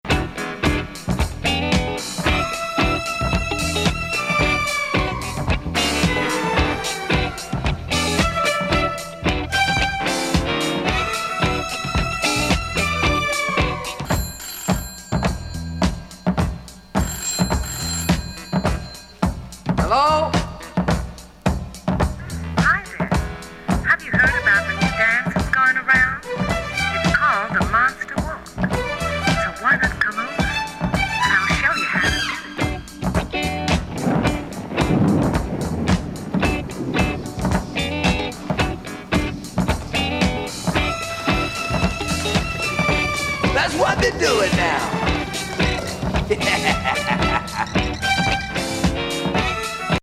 77年にリリースされたファンキー・ミディアム・ディスコ。
ストリングス+ズンドコ+電話+雷雨+怪人トークオーバー。良！！